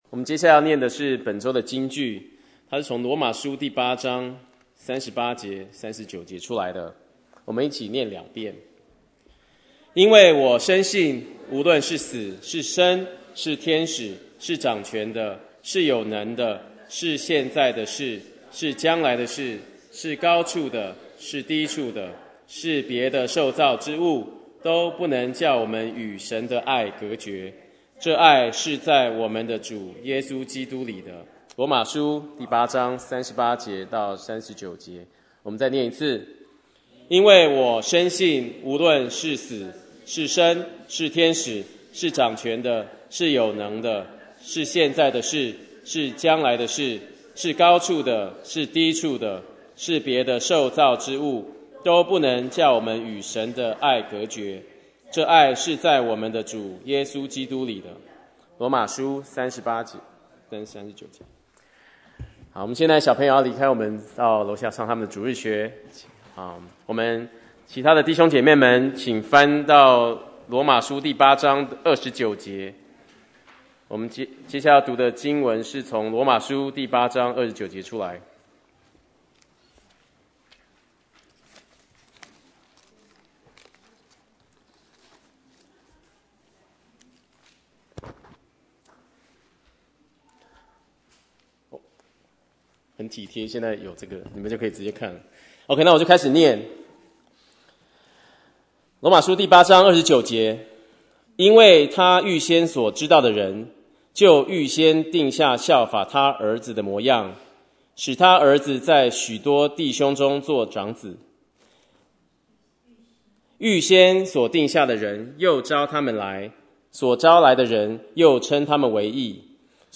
Bible Text: 羅馬書 8:29-39 | Preacher